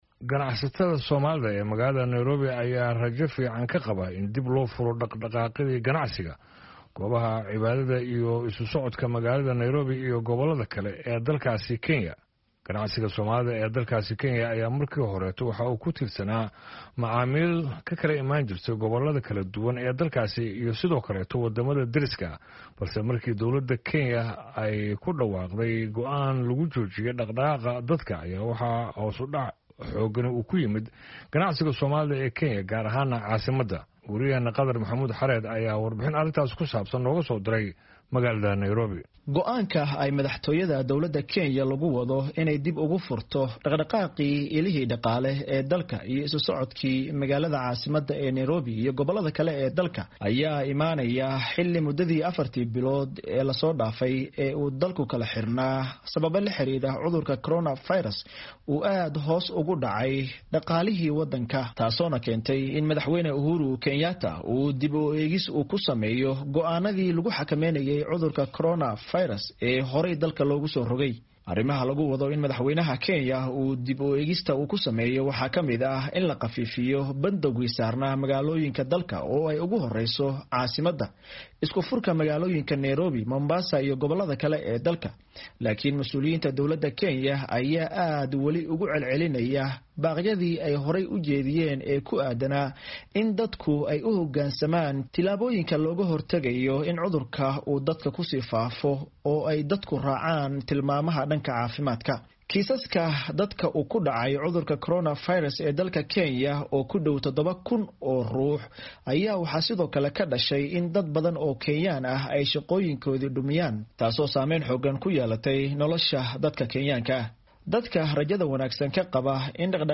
ayaa warbixinta kasoo diray Nairobi.